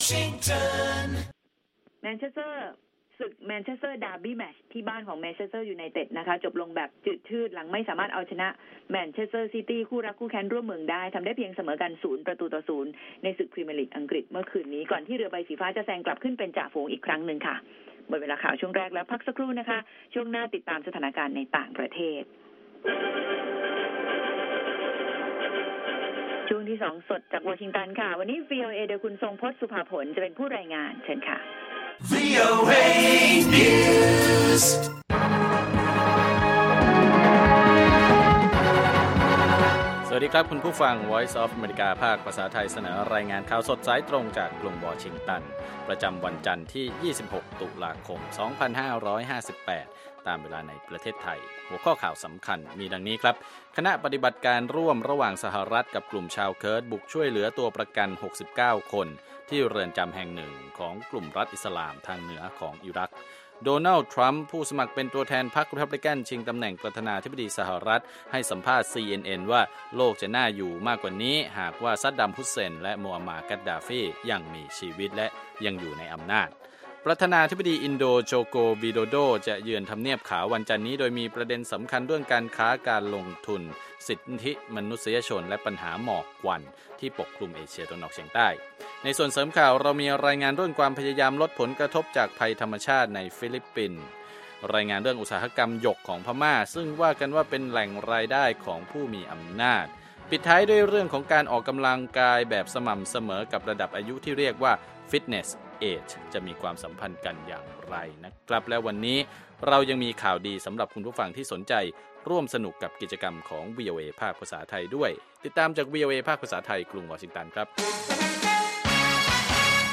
ข่าวสดสายตรงจากวีโอเอ ภาคภาษาไทย 6:30 – 7:00 น.